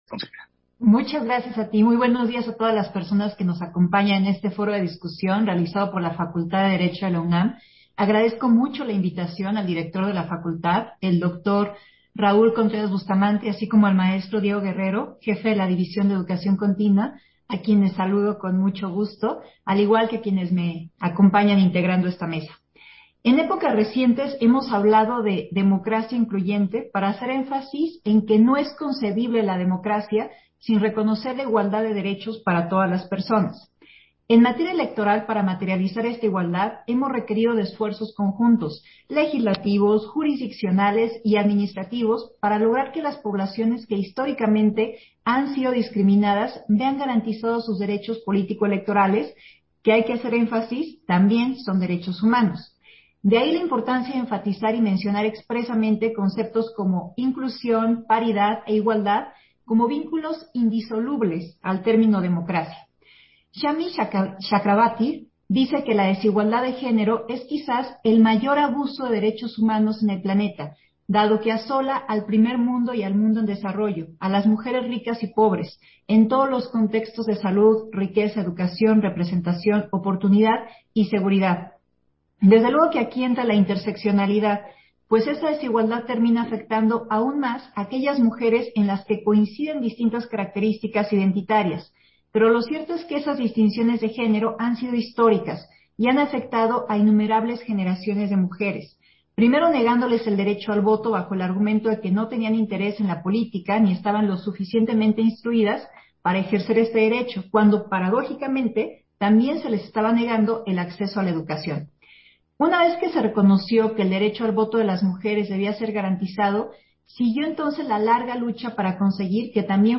Intervención de Dania Ravel, en el foro, Democracia incluyente: El camino de las acciones afirmativas